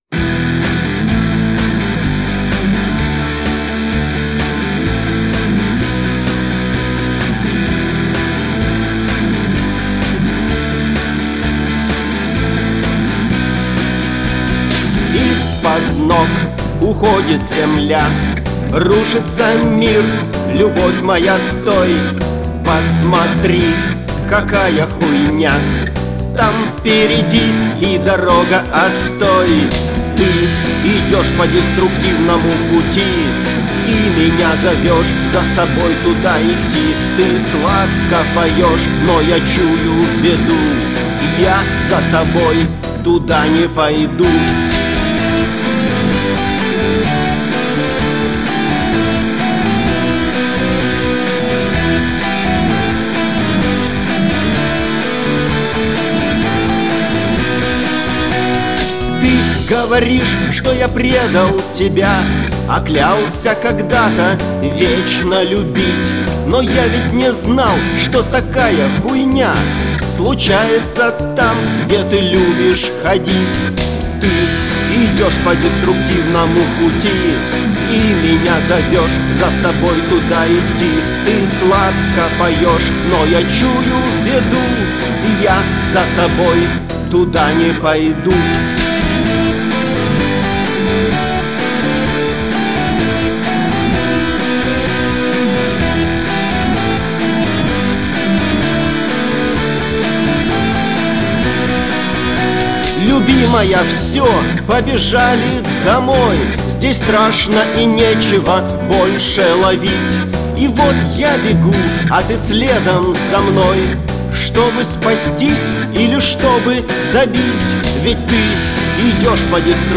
политический панк-рок